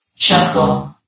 CitadelStationBot df15bbe0f0 [MIRROR] New & Fixed AI VOX Sound Files ( #6003 ) ...
chuckle.ogg